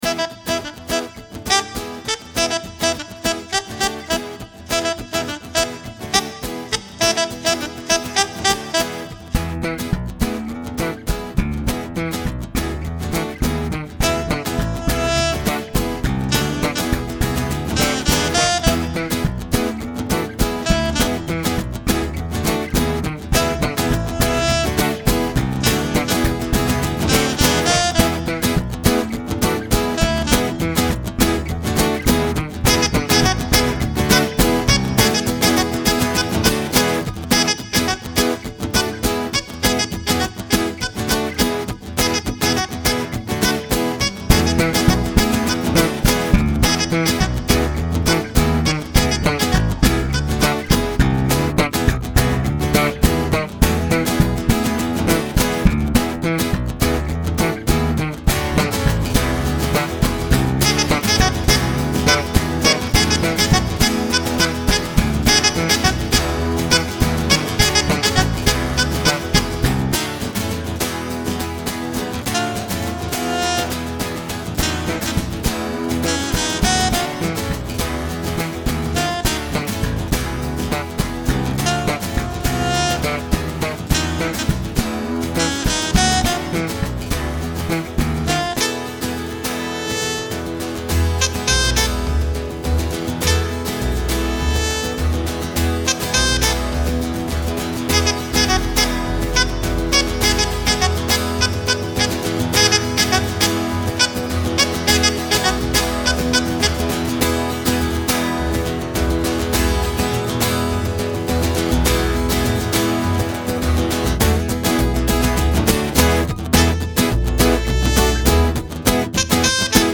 Jazzy Soundtrack
Jazzy_1.mp3